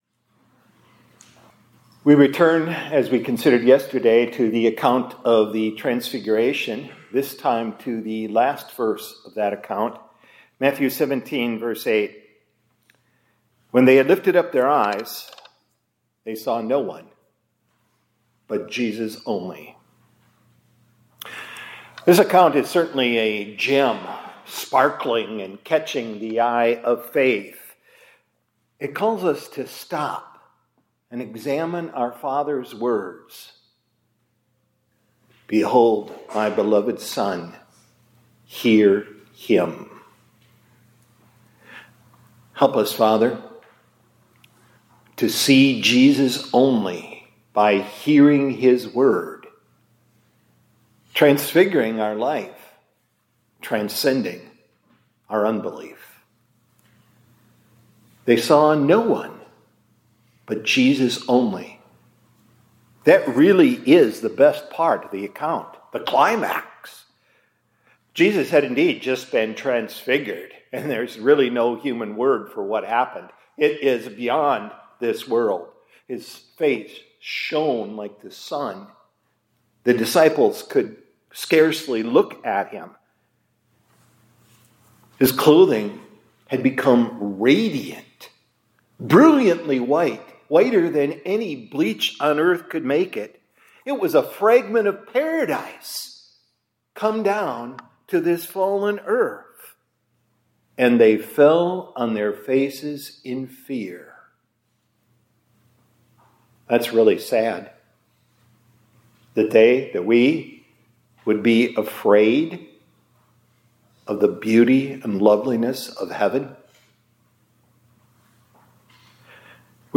2026-02-13 ILC Chapel — Help Us, Lord, to See Only Jesus By Hearing His Word